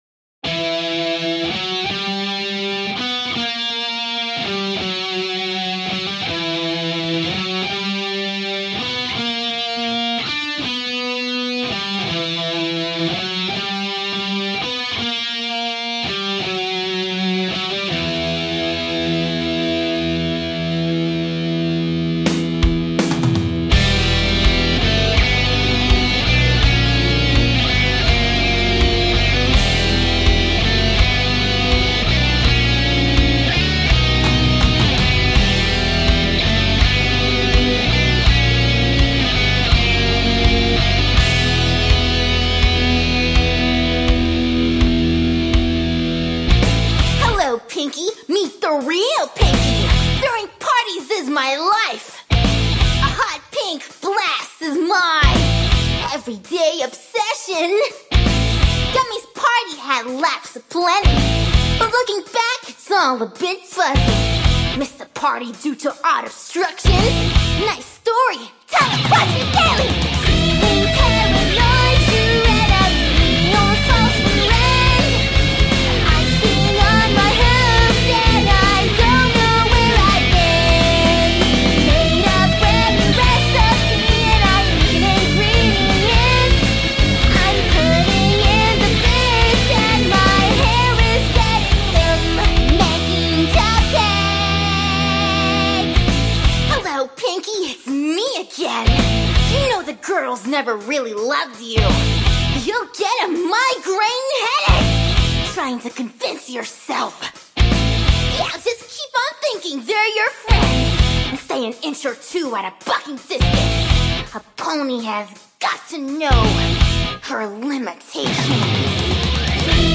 Vocals
Guitars, bass
Drums